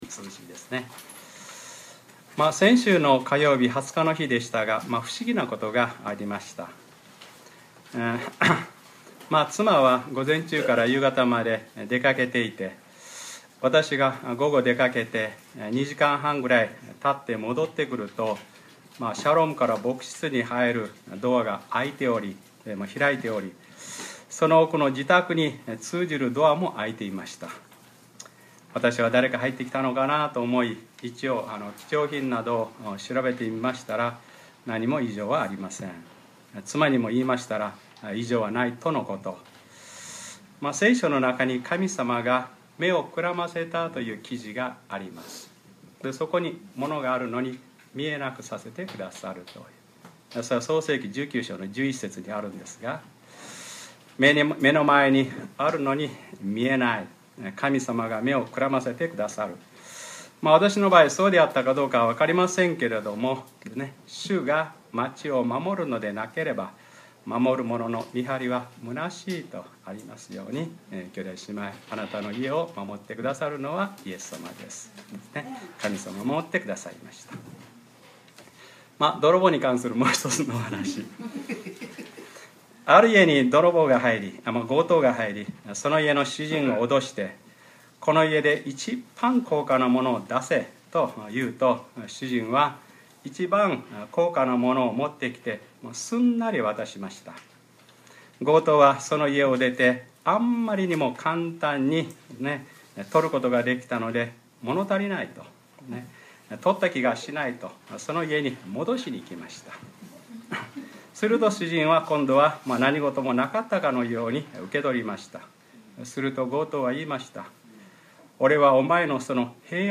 2013年8月25日(日）礼拝説教 『黙示録ｰ２２ ひとりでも滅びることを望まず』